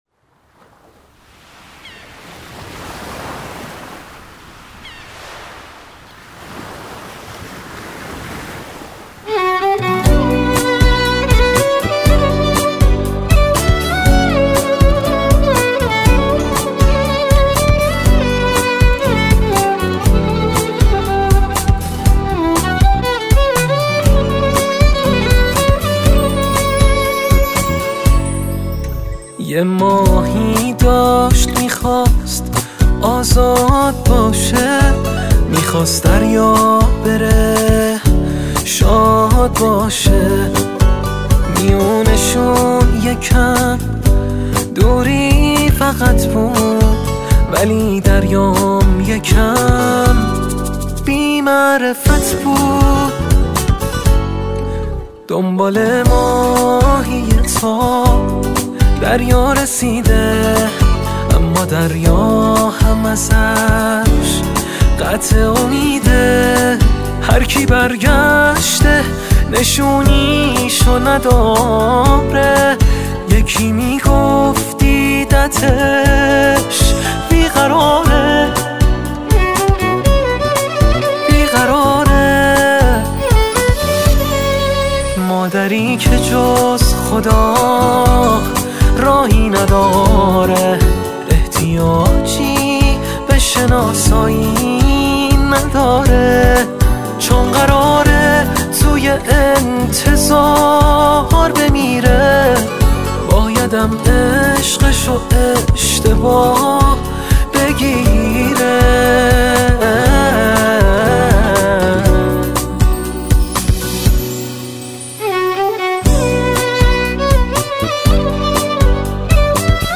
گیتار
ویولن